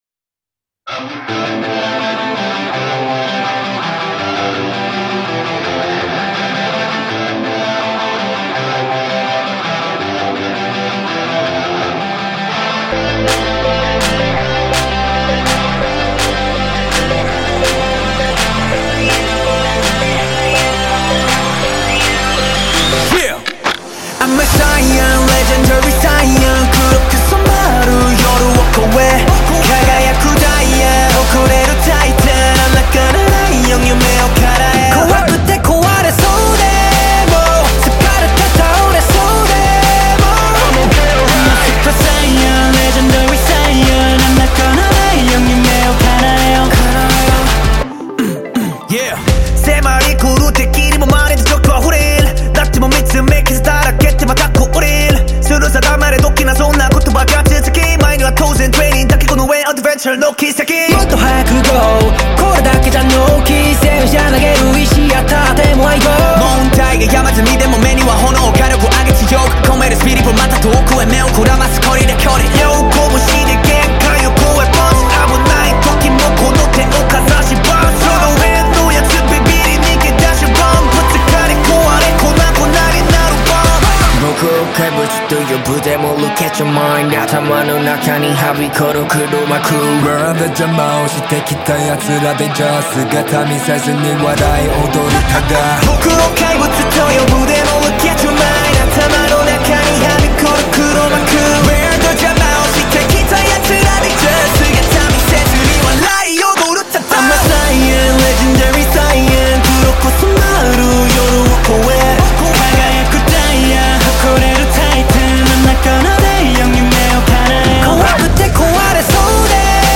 KPop Song
Label Dance